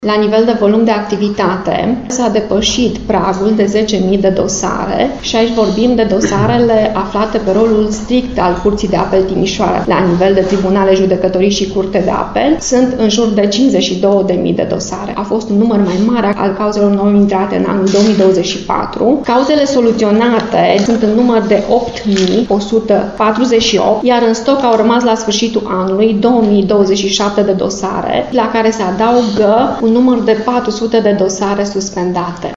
Ca volum de muncă s-a depășit nivelul de 50.000 de dosare, mai spune președintele Curții de Apel Timișoara, Maria Dica.